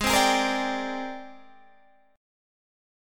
AbM#11 chord